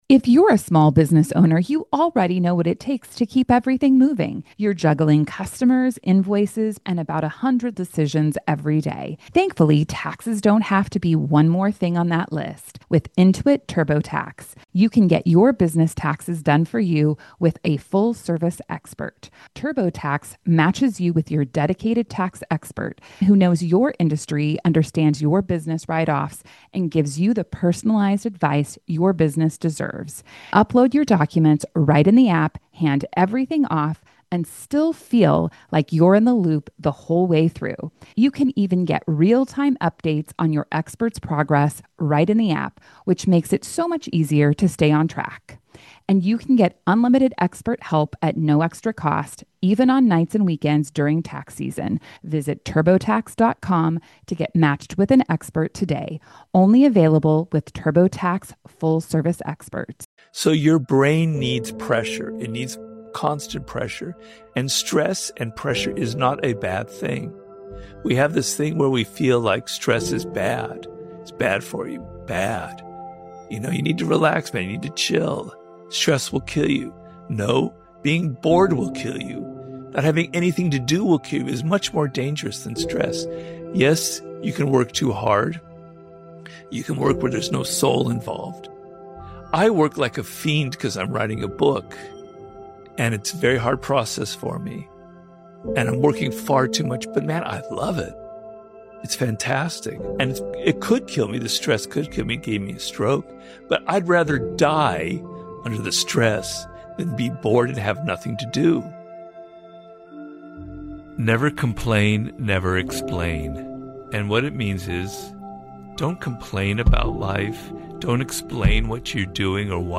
Robert Greene - How to master self control motivational speech